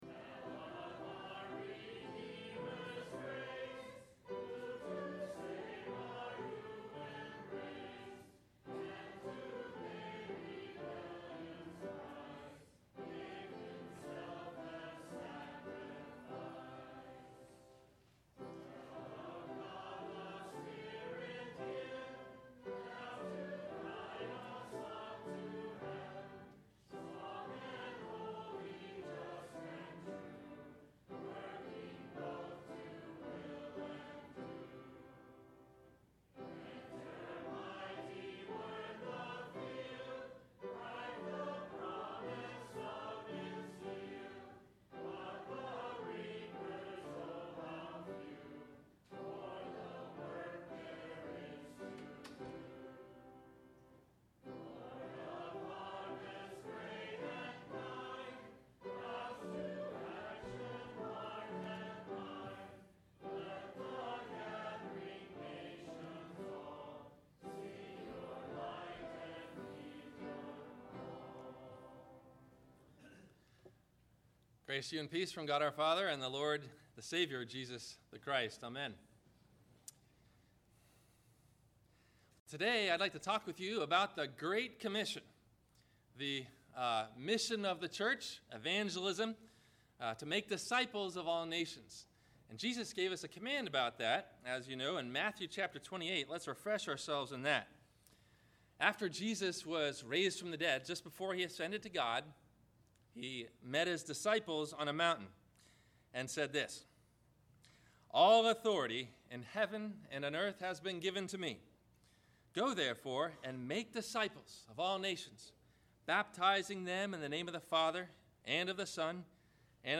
One God – Three Persons – Witnessing to Jehovahs Witnesses – Sermon – May 30 2010